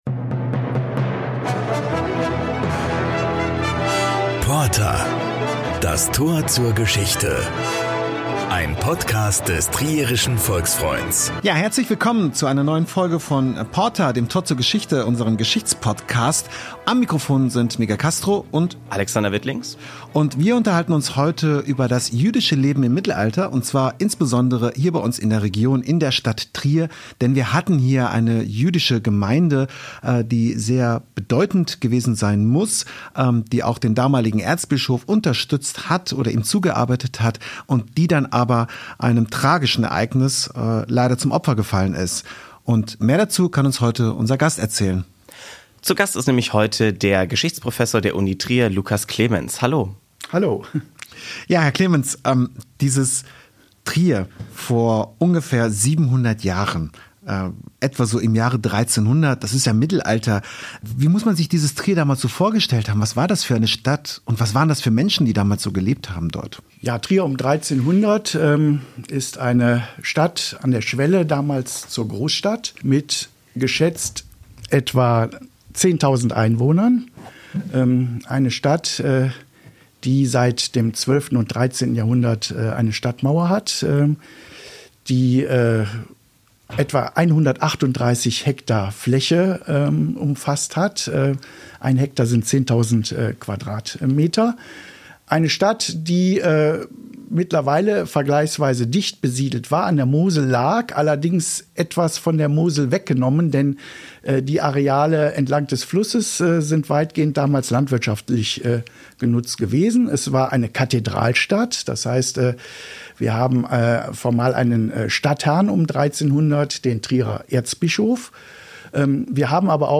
Ein Professor der Universität Trier gibt Auskunft.